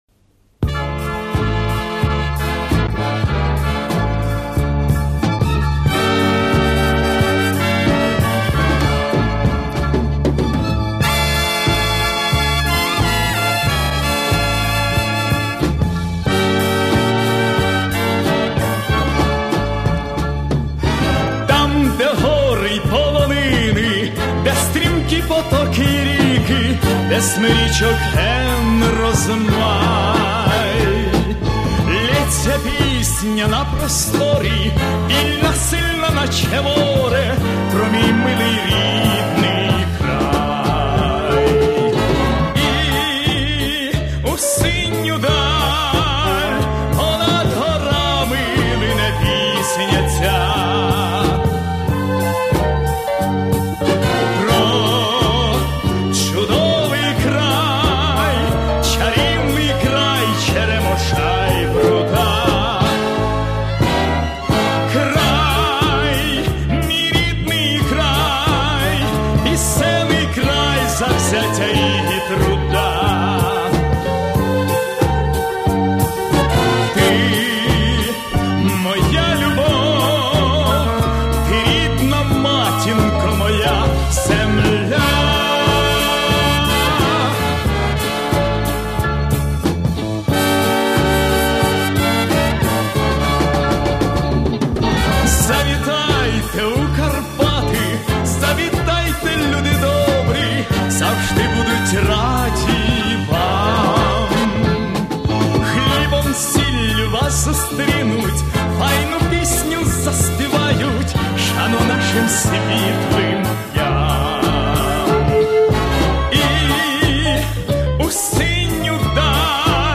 музыканты студии звукозаписи